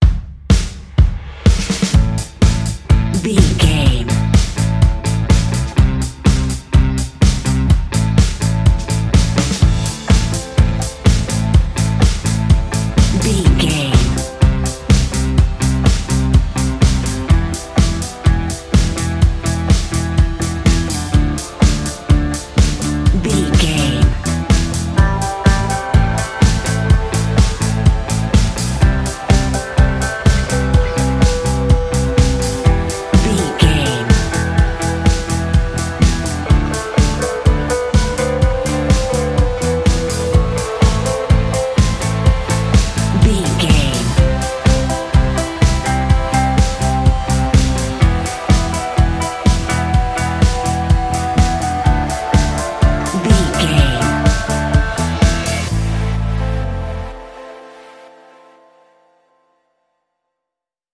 Fast paced
Ionian/Major
Fast
intense
edgy
electric guitar
aggressive
indie rock
bass guitar
synthesiser